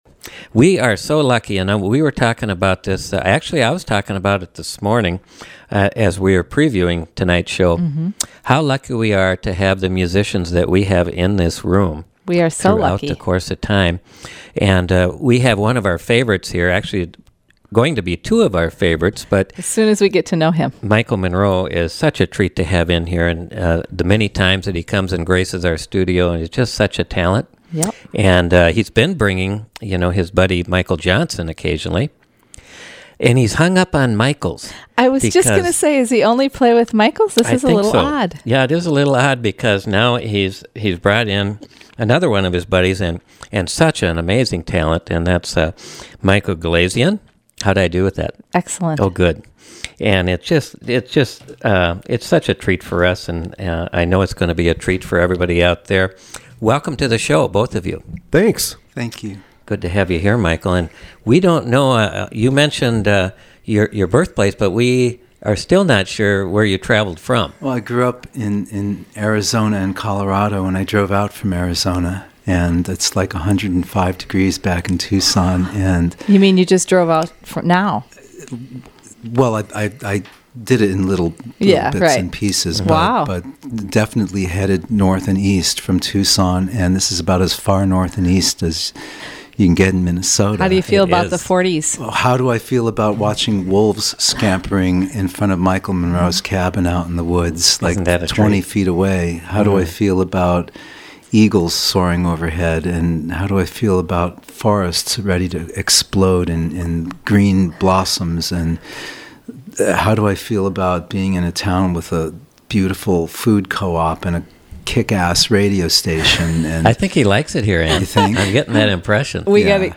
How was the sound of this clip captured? Live Music Archive